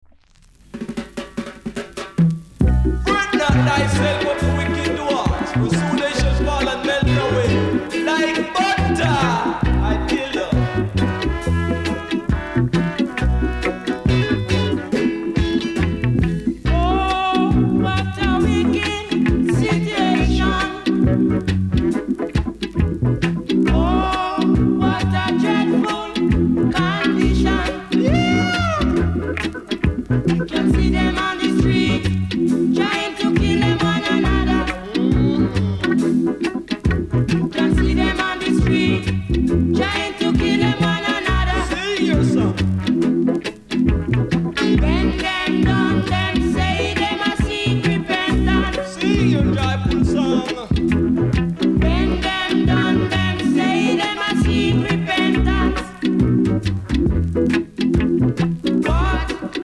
※出だしで少しジリジリします。ほか小さなチリノイズが少しあります。
コメント NICE DEEJAY!!